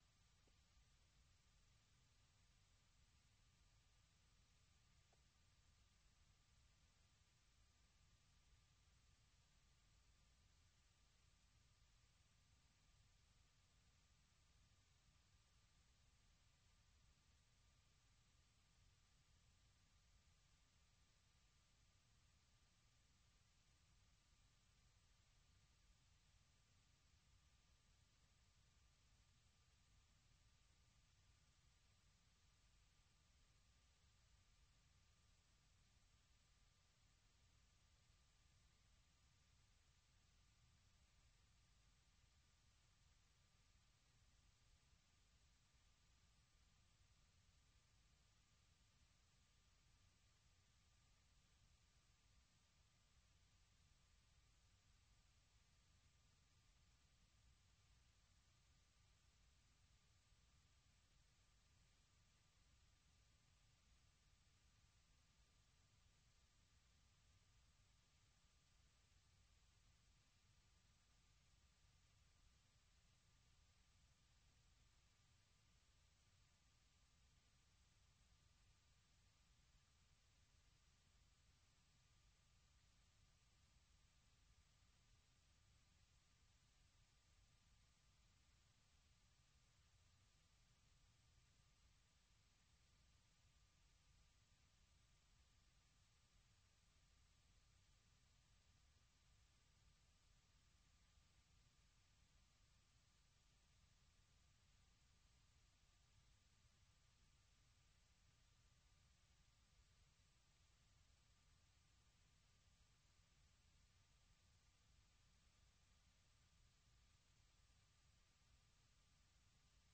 Le programme quotidien d'appel de VOA Afrique offre aux auditeurs un forum pour commenter et discuter d'un sujet donné, qu'il s'agisse d'actualités ou de grands sujets de débat.